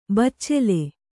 ♪ baccele